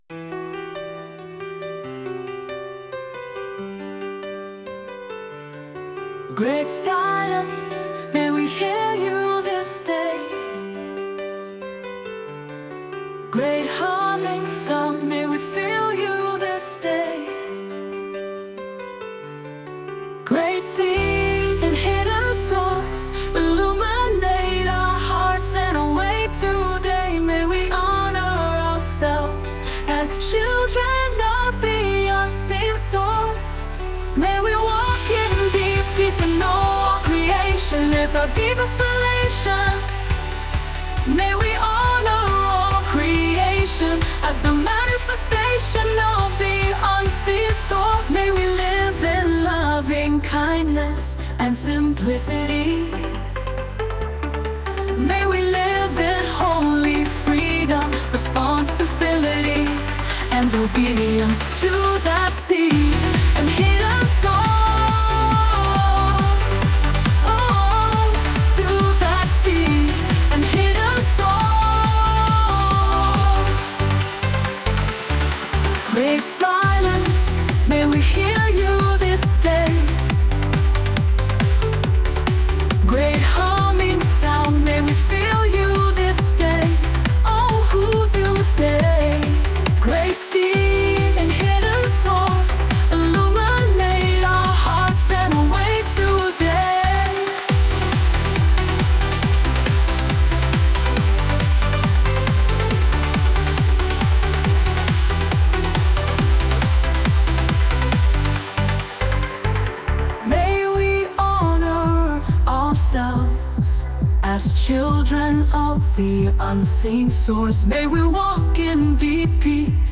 4th Order Simple Prayer